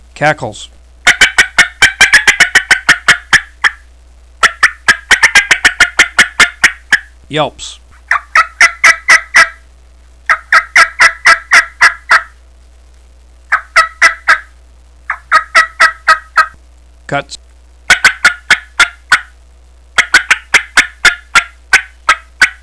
Yellow Yelper 3 Reed, 2 Cutt Mouth Call
Listen to 22 seconds of cackles, yelps, & cutts